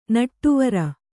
♪ naṭṭuvara